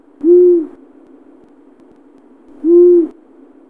Hibou moyen duc
Asio otus